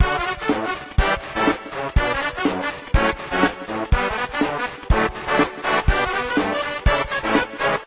a real boy Meme Sound Effect
This sound is perfect for adding humor, surprise, or dramatic timing to your content.